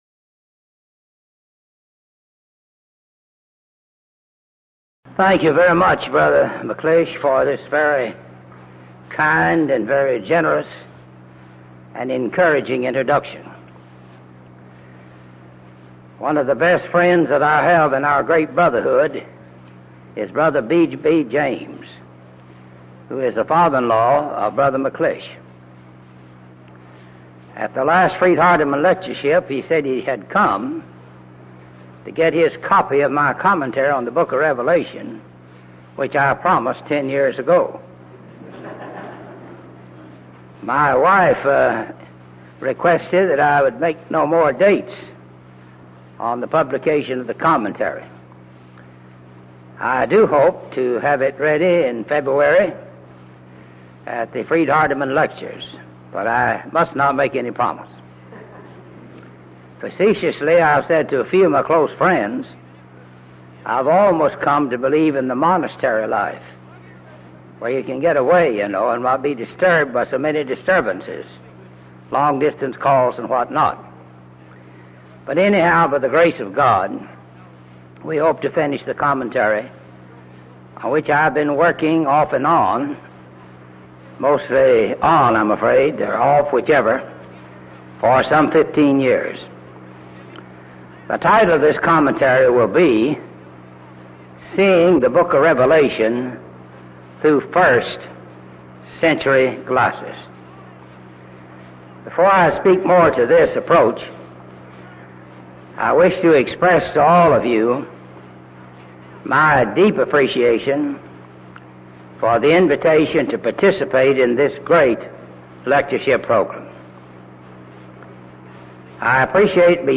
Event: 1984 Denton Lectures
lecture